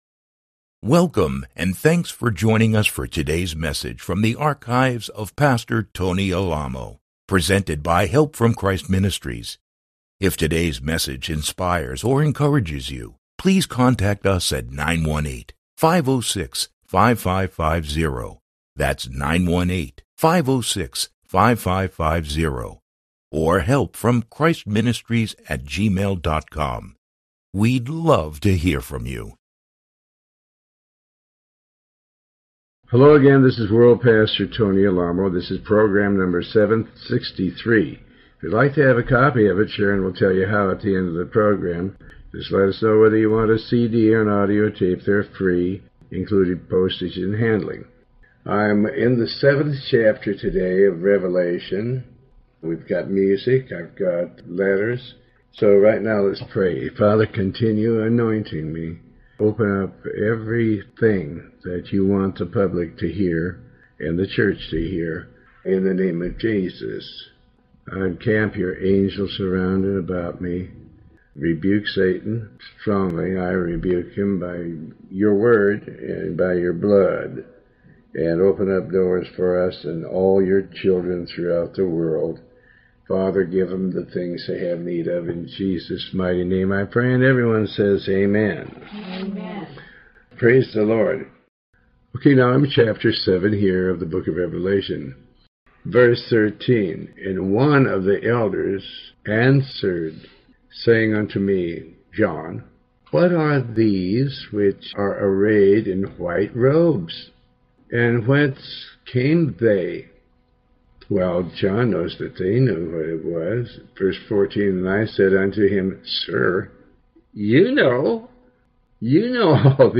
Pastor Alamo reads and comments on the Book of Revelation chapters 7-9. This program is part of a series covering the entire Book of Revelation